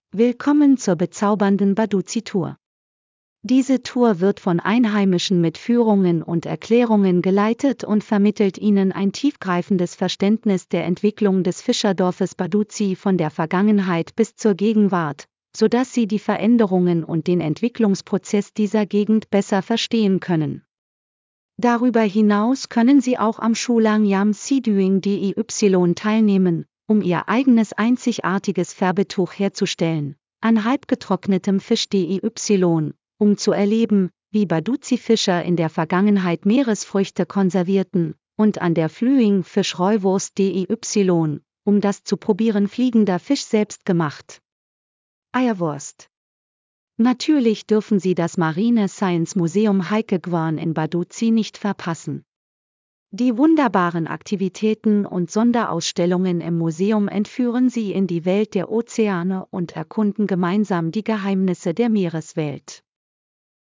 Einminütige kostenlose Probe der Audioführung dieser Strecke